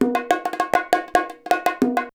100 BONGO8.wav